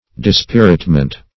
Search Result for " dispiritment" : The Collaborative International Dictionary of English v.0.48: Dispiritment \Dis*pir"it*ment\, n. Depression of spirits; discouragement.